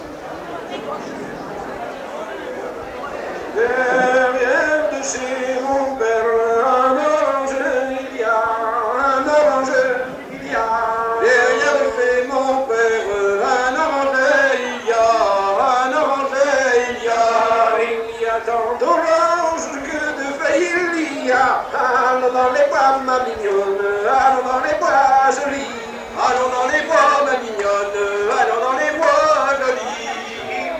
Hanter dro
Entendu au fest-noz de Monterfil en juin 88